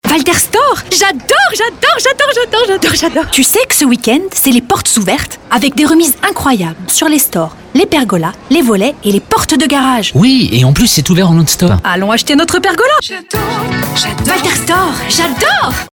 Réalisation de messages radio, campagne sur toute l’année, pour Walter Stores (Réseau Maisons de France) et ses déclinaisons avec un jingle sonore original fédérateur.